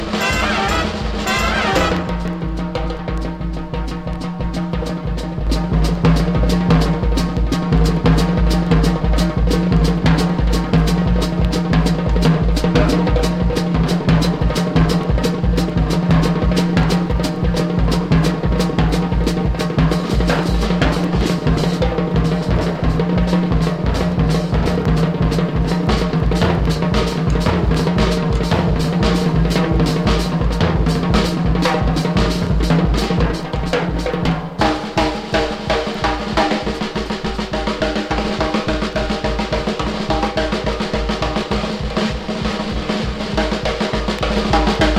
舞台音楽ということで、耳馴染みのあるメロディや叙情豊かな印象が響きます。
Jazz, Stage & Screen　France　12inchレコード　33rpm　Stereo